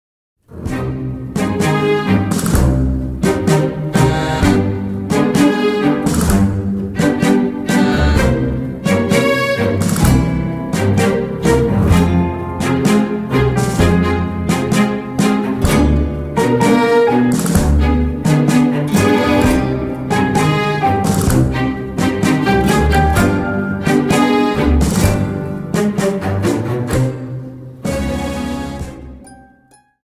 Dance: Tango 32 Song